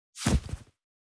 434,物を置く,バックを置く,荷物を置く,トン,コト,ドサ,ストン,ガチャ,ポン,タン,スッ,ゴト,
効果音室内物を置く